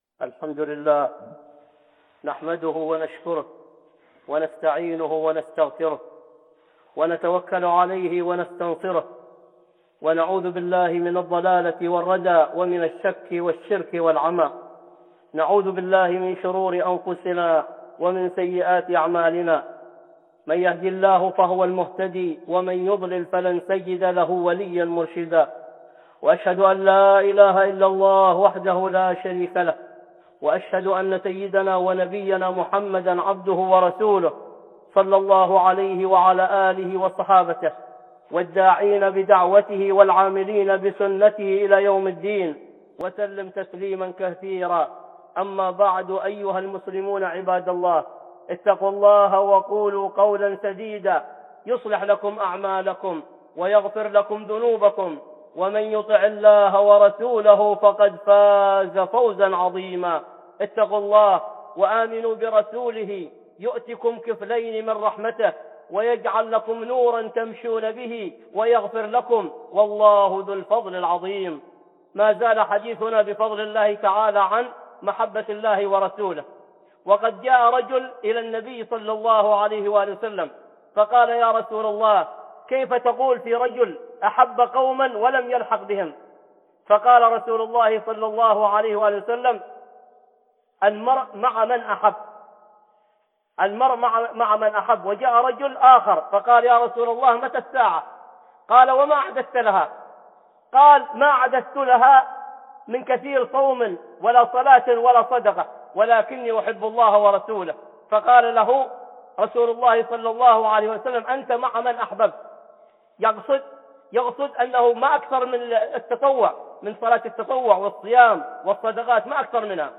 (خطبة جمعة) محبة ألله ورسوله 2